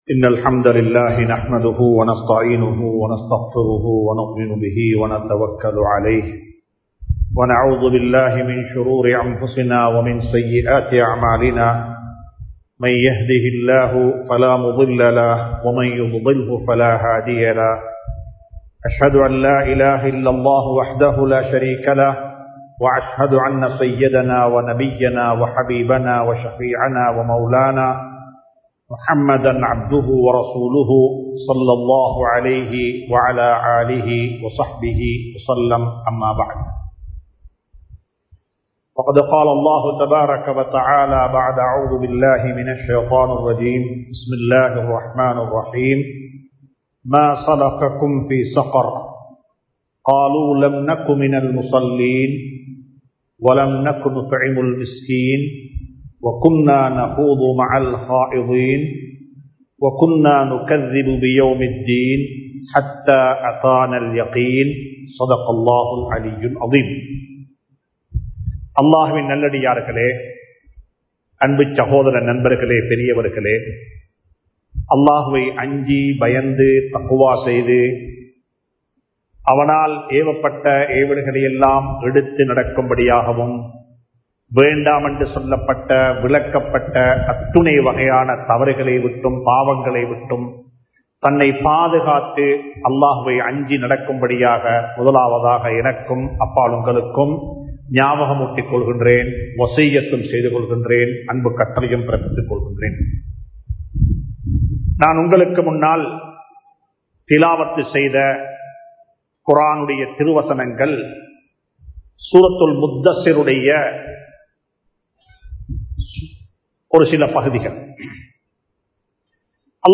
ஸகர் என்ற நரகத்திற்கு தகுதியானவர்கள் | Audio Bayans | All Ceylon Muslim Youth Community | Addalaichenai